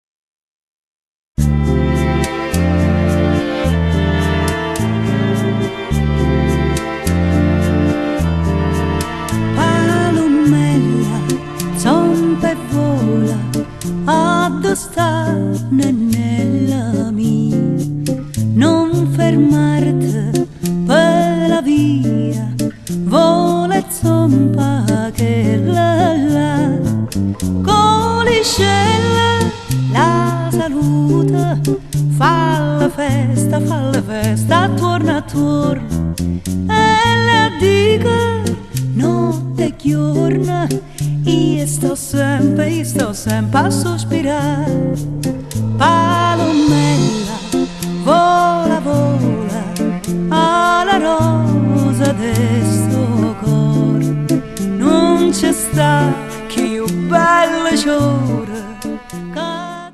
vocals
electric basses, stick, midi
percussion
violin
viola
violoncello
raffinata e nostalgica